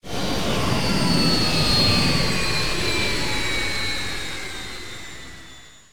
powerdown.ogg